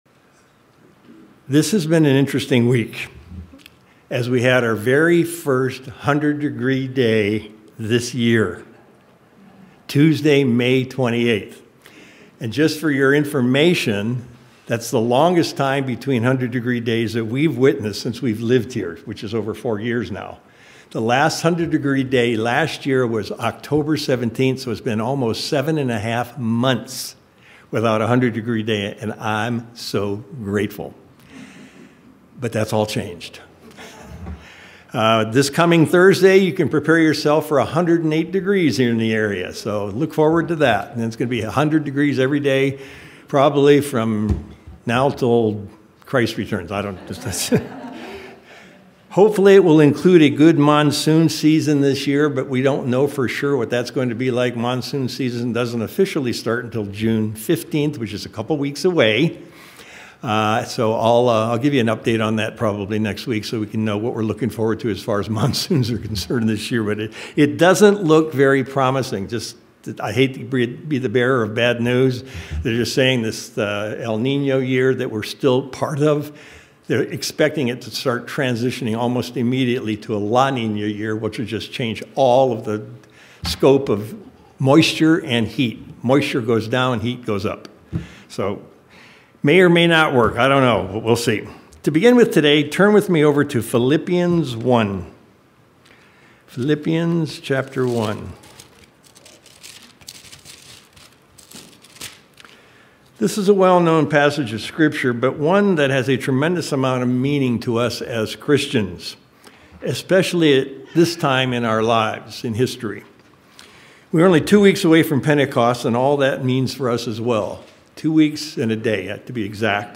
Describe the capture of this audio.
Given in El Paso, TX Tucson, AZ